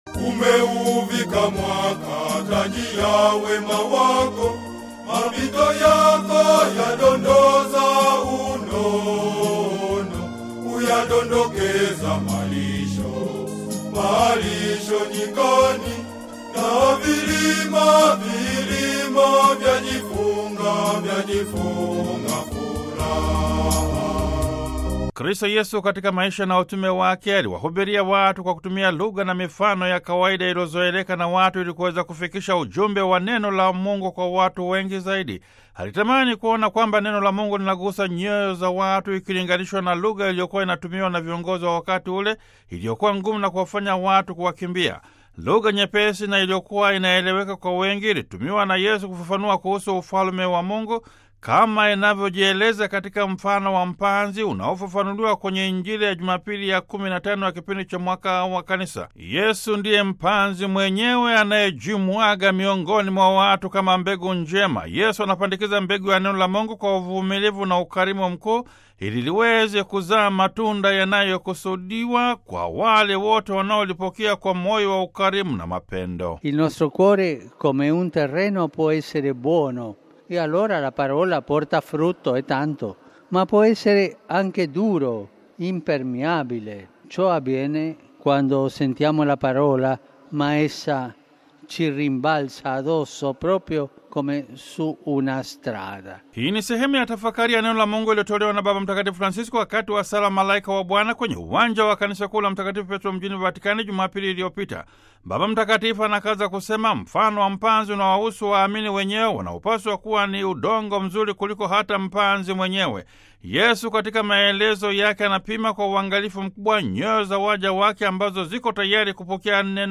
Hii ni sehemu ya tafakari ya Neno la Mungu iliyotolewa na Baba Mtakatifu Francisko wakati wa Sala ya Malaika wa Bwana kwenye Uwanja wa Kanisa kuu la Mtakatifu Petro mjini Vatican, Jumapili tarehe 16 Julai 2017.